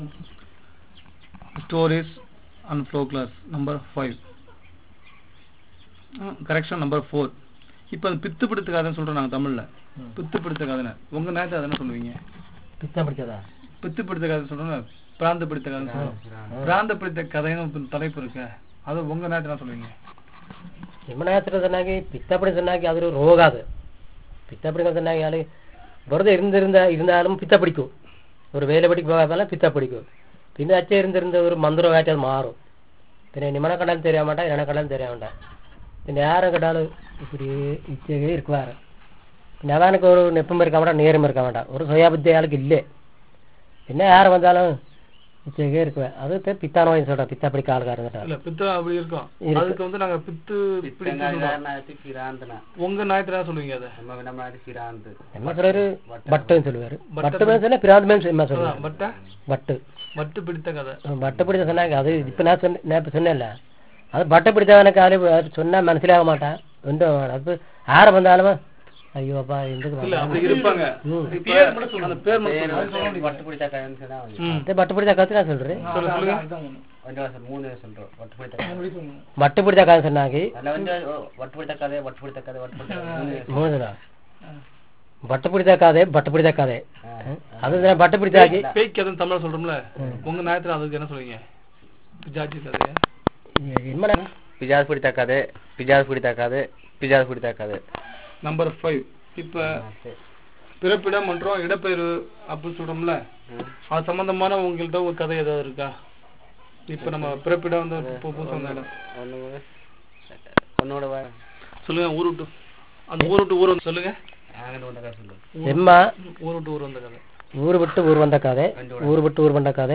Personal narrative on exorcism, migration, animals in forest, difficulty in jobs